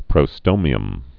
(prō-stōmē-əm)